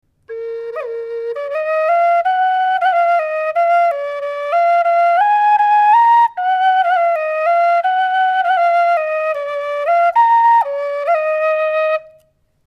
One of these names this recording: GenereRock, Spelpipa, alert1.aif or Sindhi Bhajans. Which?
Spelpipa